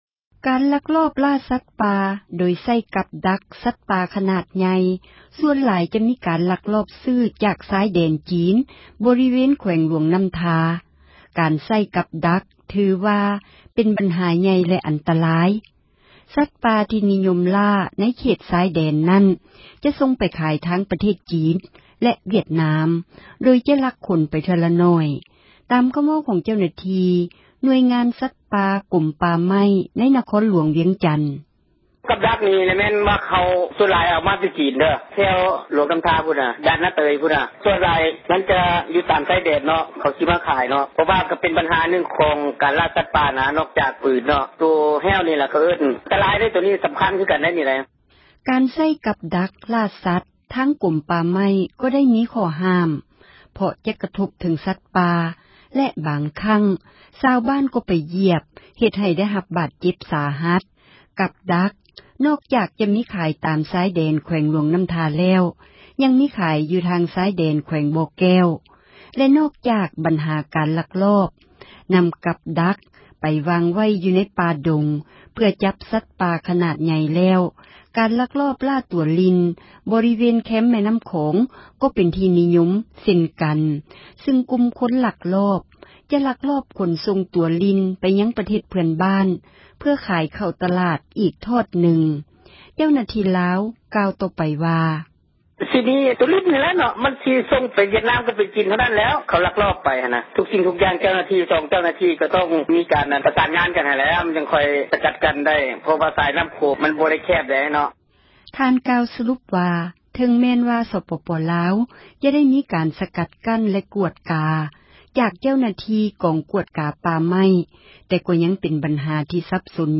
ຕາມຄໍາເວົ້າ ຂອງເຈົ້າໜ້າທີ່ ໜ່ວຍງານສັດປ່າ ກົມປ່າໄມ້ໃນ ນະຄອນຫລວງວຽງຈັນ: